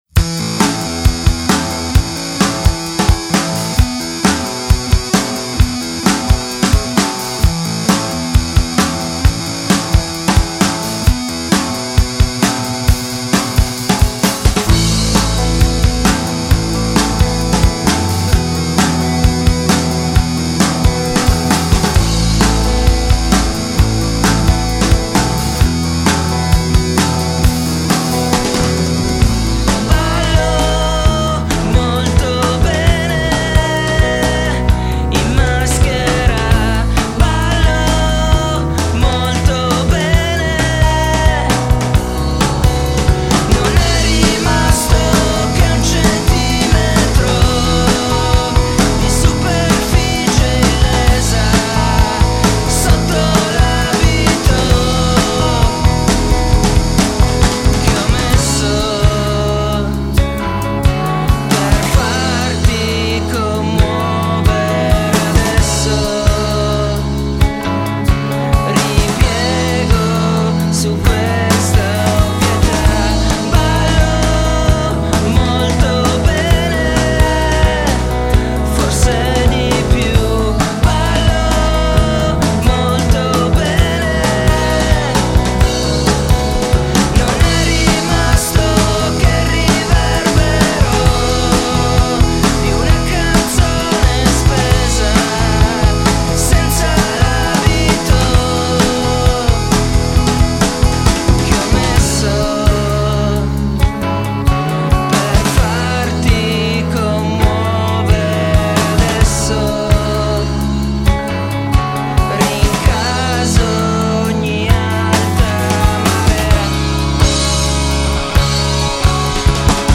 MUSIC>alternative rock